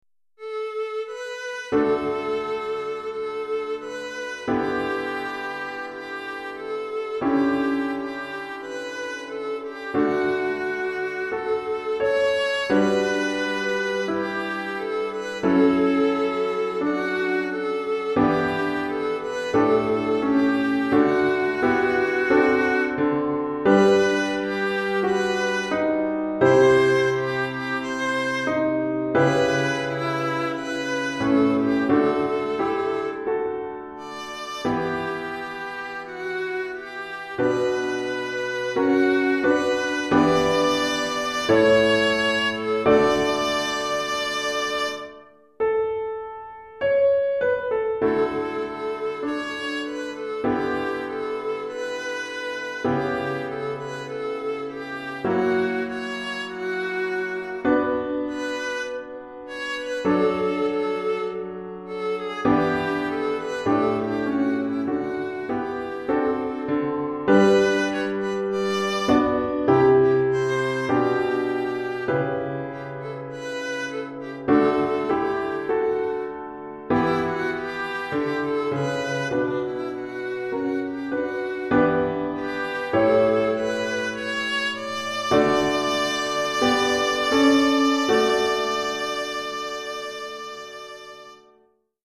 Alto et Piano